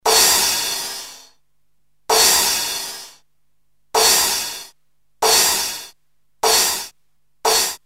edit ENVELOPE only the sample decay can be set for each percussion.
decay demo
Hear decay crash